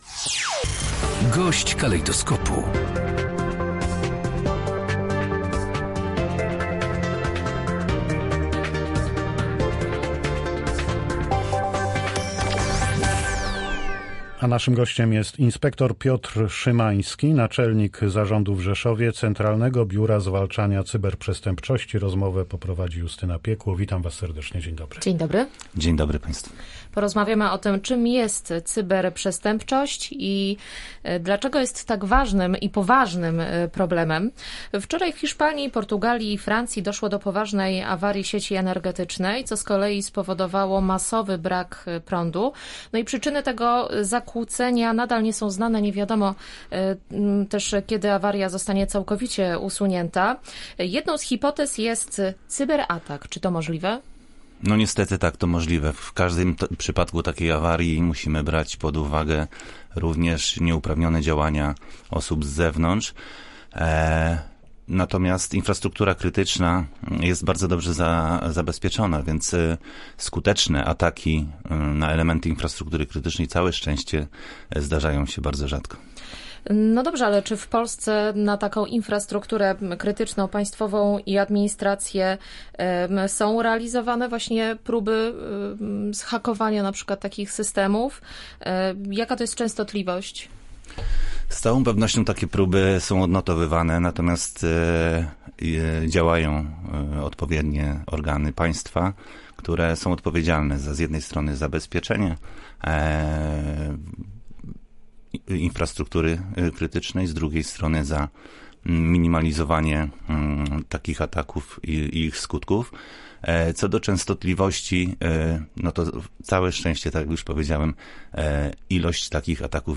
Gość Polskiego Radia Rzeszów zauważa, że w ochronie przed przestępcami istotne jest zachowanie cyberhigieny.